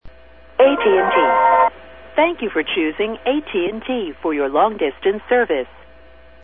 The old style (version 1) Inter-LATA verification recording of AT&T long distance company.